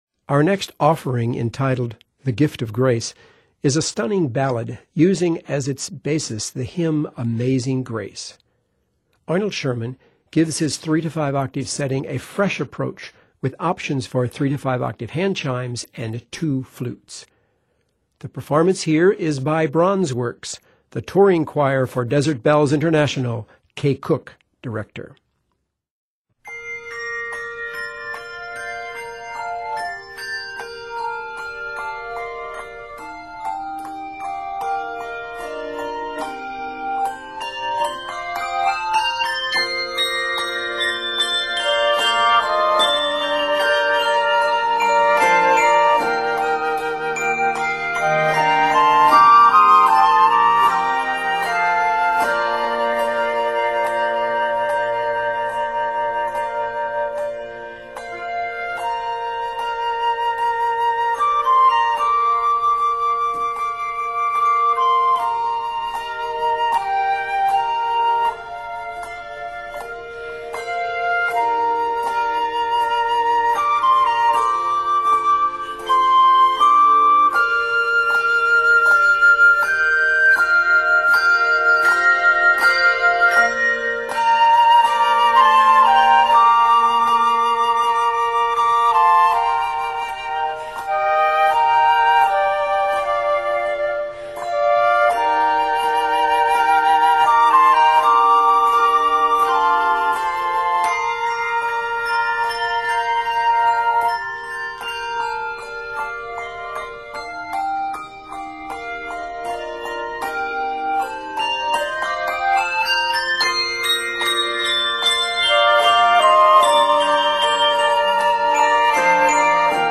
or 5 octaves of handchimes